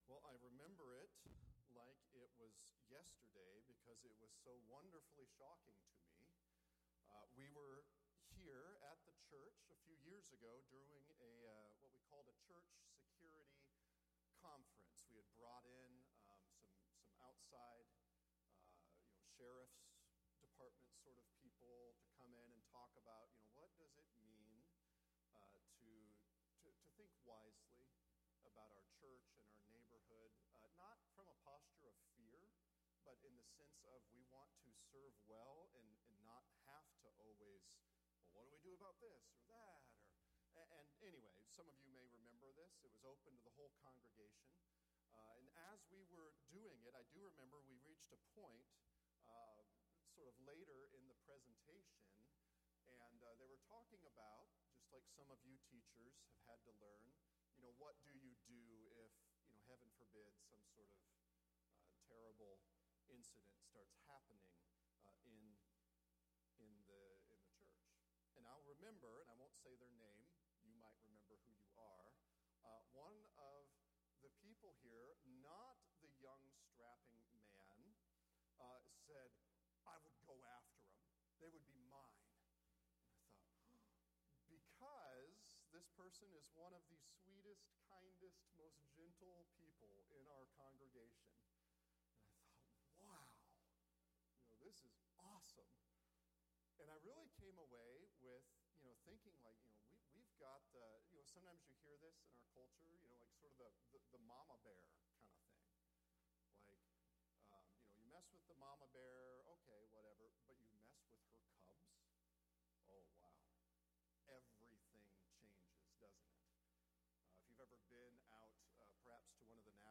– Sermons